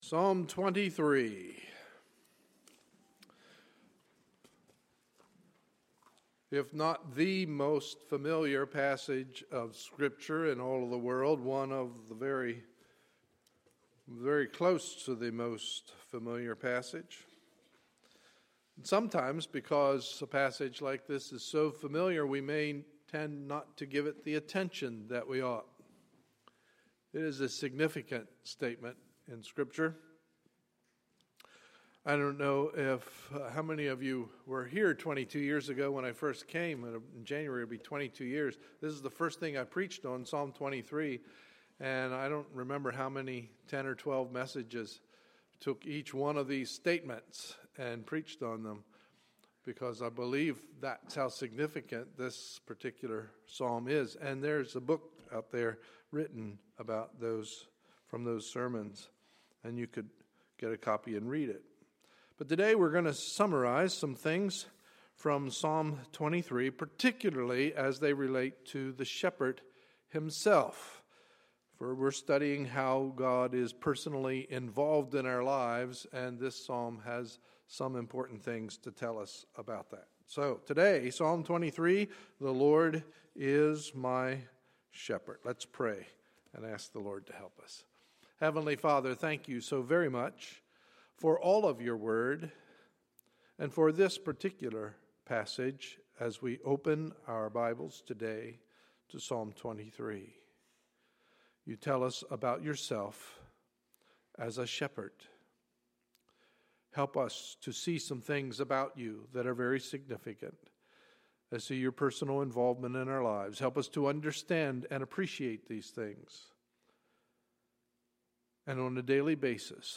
Sunday, October 13, 2013 – Morning Service
Sermons